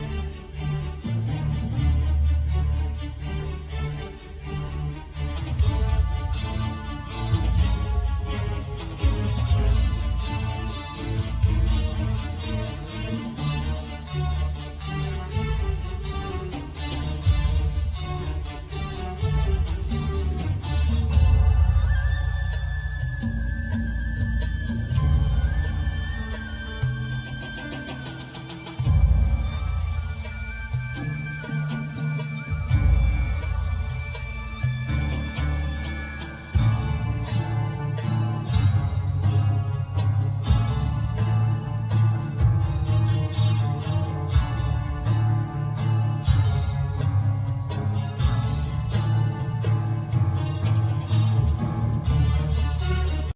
Ud, Bendir, Nay, Turkish Qanun, Tajira, Darbuka,Keyboard